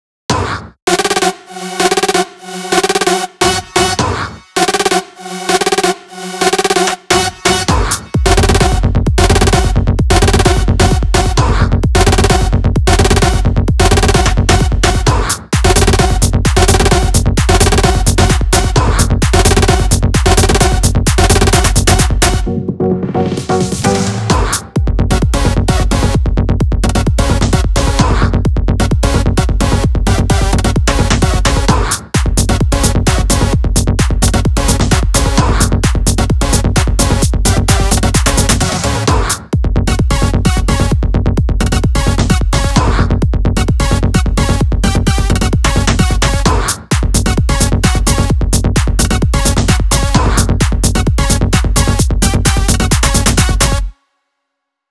S_Audio_265.WAV מקצב חדש, אשמח להערות!
זה רק הדרופ עדיין לא בניתי את כל המקצב